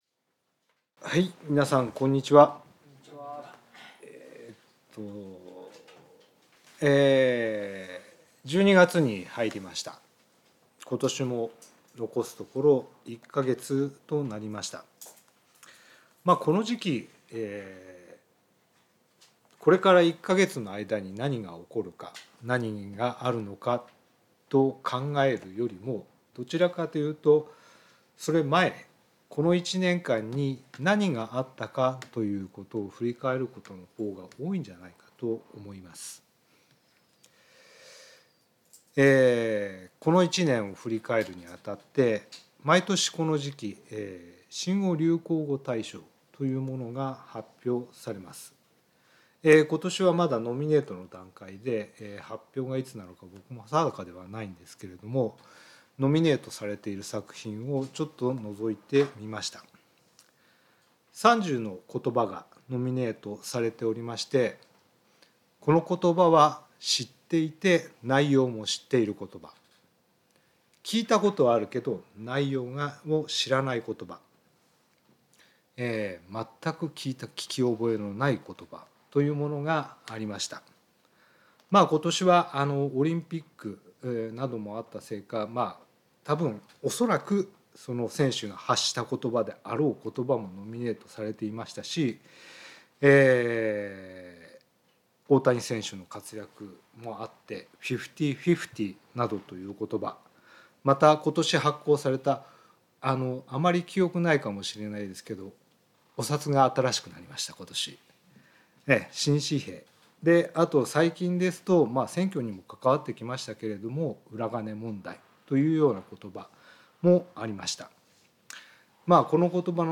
聖書メッセージ No.244